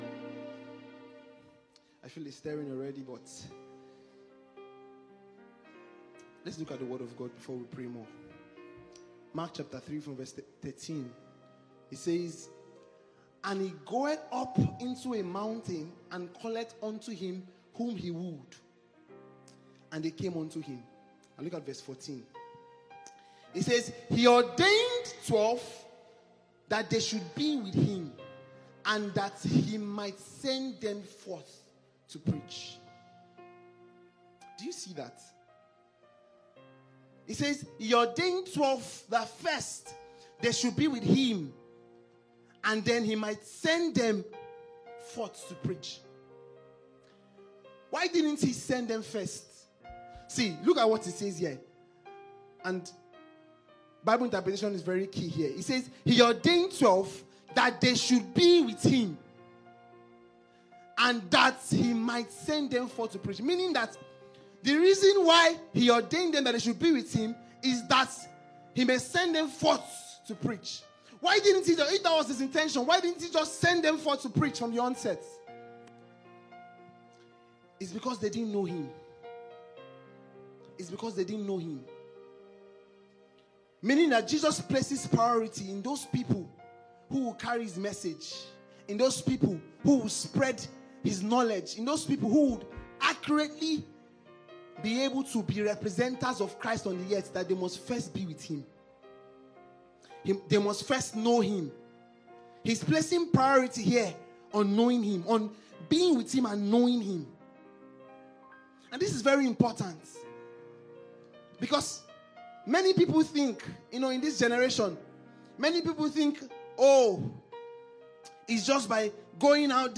Special Prayer Service.mp3